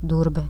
Lv-Durbe.ogg